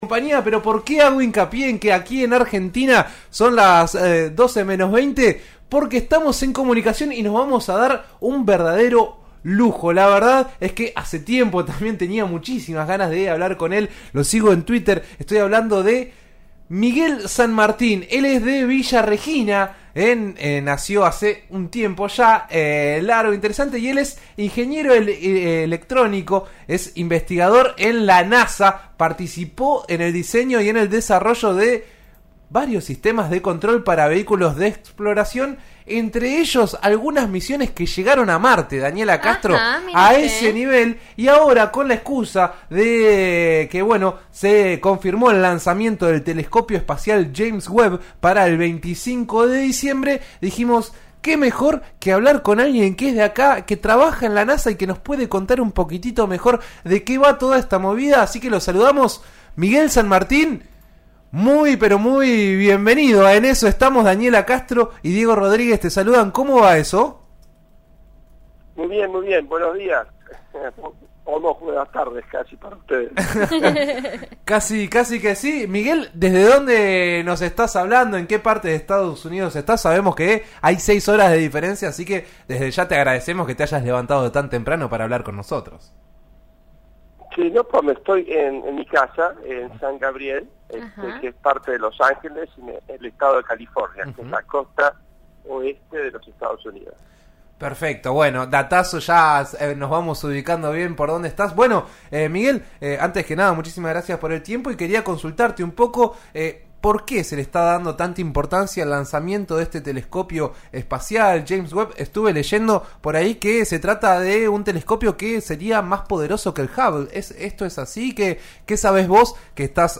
A partir del lanzamiento del telescopio espacial James Webb, En eso estamos de RN Radio (89.3) dialogó